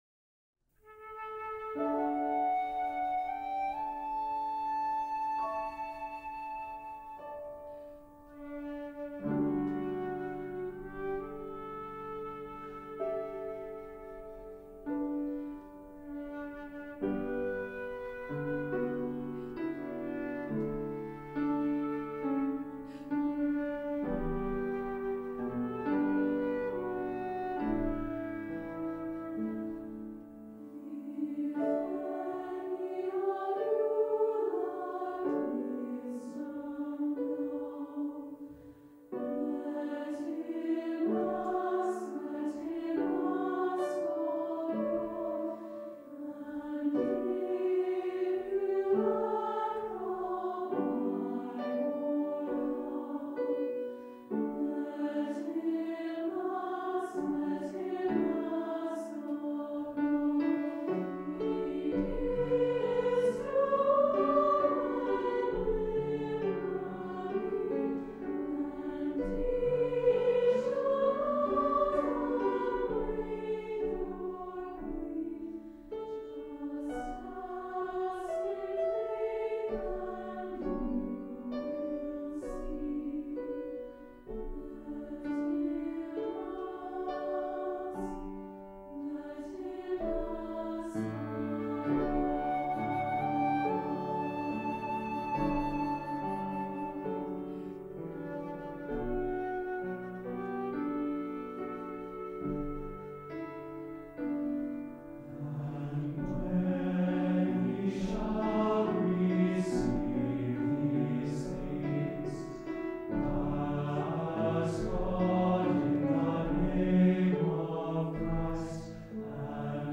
SATB Choir, Flute, and Piano